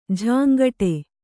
♪ jhāŋgaṭe